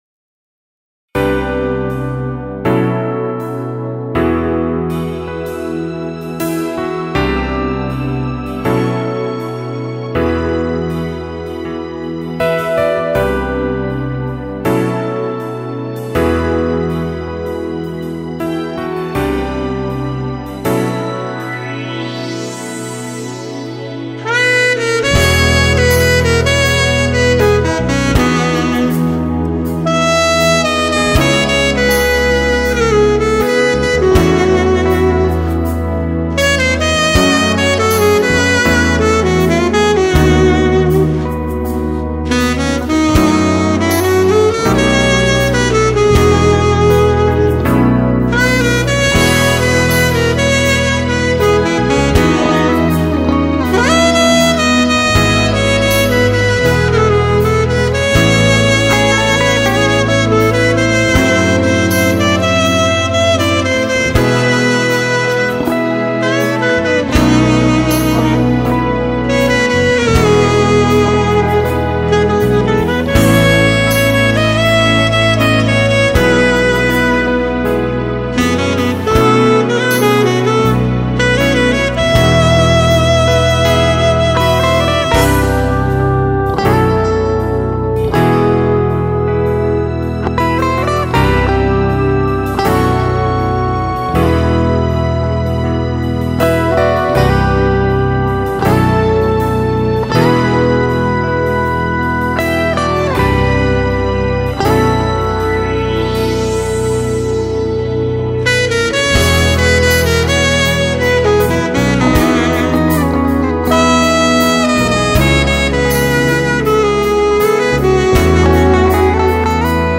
Sax Alto